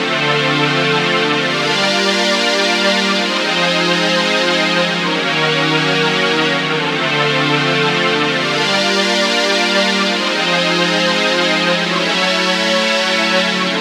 Pad_139_D.wav